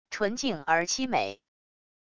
纯净而凄美wav音频